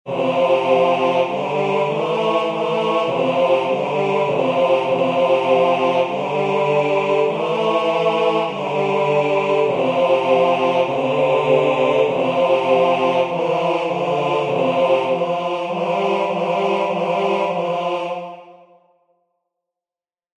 Die Distinktion Beispiel 2a) steht im deutero E, in qua ditoni occursus vel simplex vel intermissus placet; 2b) in proto D, mit einem occursus im Einklang am Ende; in der Distinktion 2c) steigt das Organum über den Gesang und während es am Schluss das f hält, macht die Oberstimme eine Art Neuma; in 2d) liegt es, wie eine Art Falso bordone, auf demselben Ton, während der Gesang auf- und abwärts sich hindurchbewegt.
Diaphonie, Notenbeispiele nach Guido von Arezzo